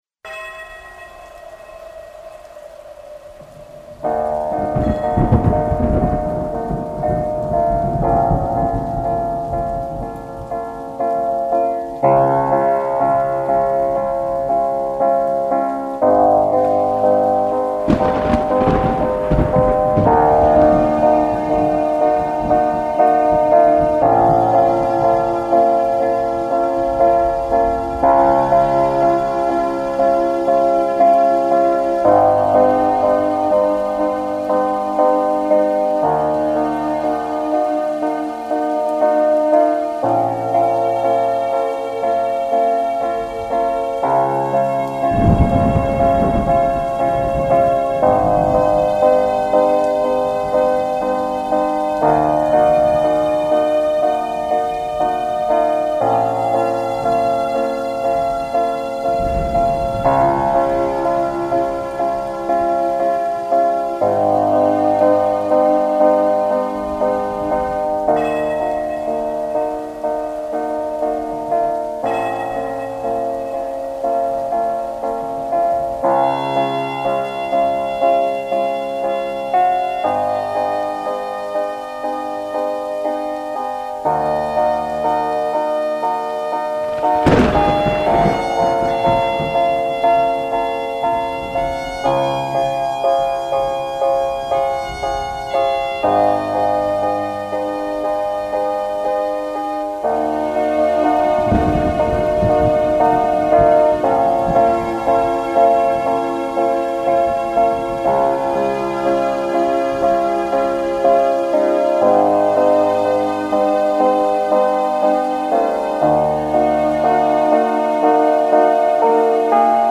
dance/electronic
Ambient